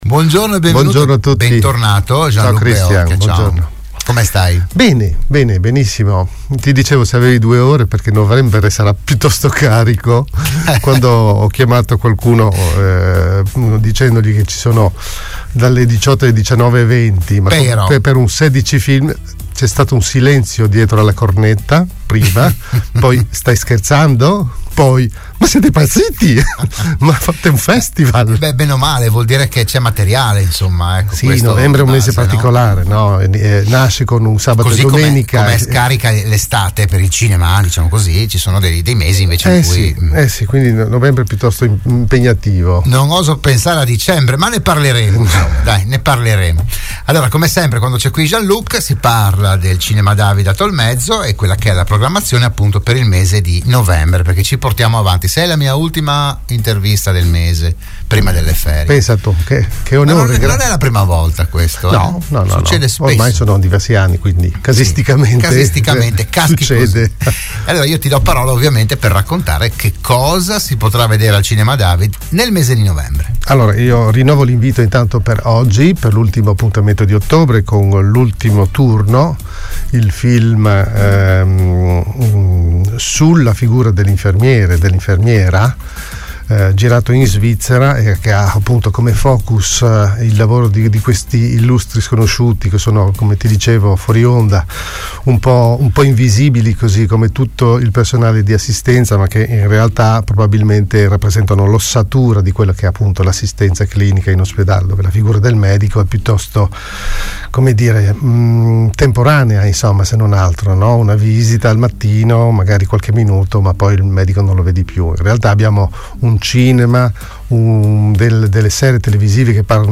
“RadioAttiva“, la trasmissione di Radio Studio Nord